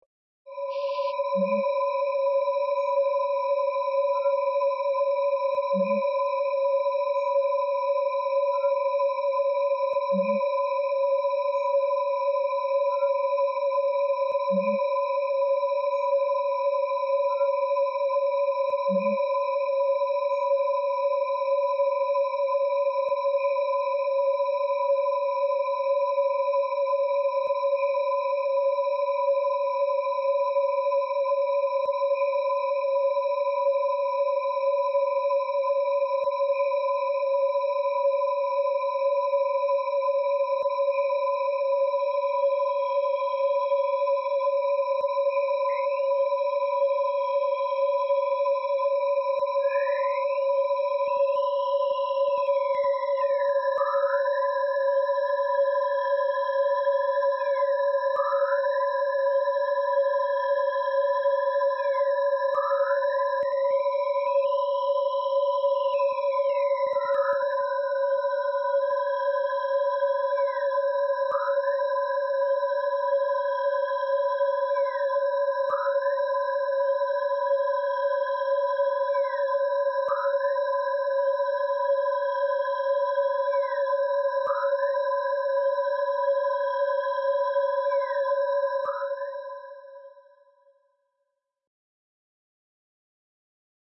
气氛和氛围 " atmos5
描述：环境，紧张的音景和隆隆声基于正在运行的火车内的环境/声场麦克风录音。
Tag: 地铁 电子 声景 混响 氛围 处理 奇怪 隆隆声 C inematic 火车 介绍 高铈 戏剧 科幻 噪声 大气 地铁